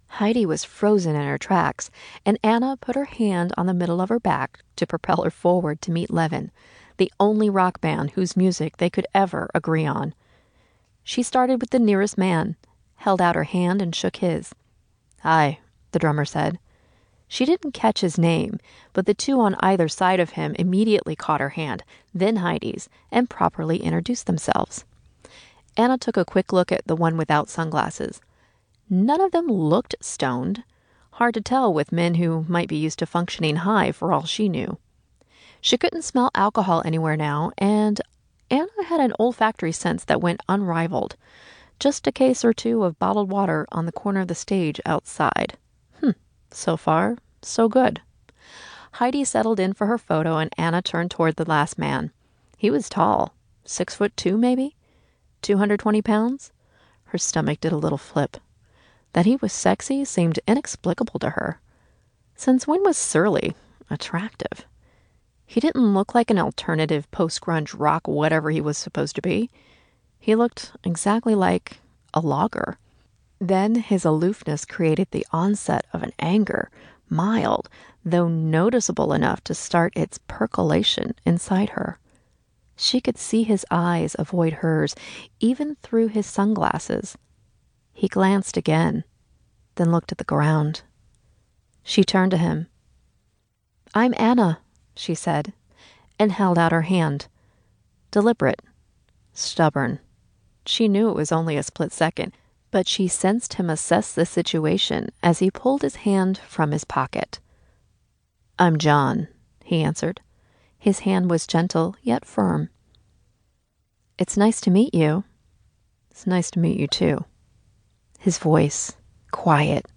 Audiobook (Romance/YA):